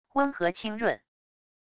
温和清润wav音频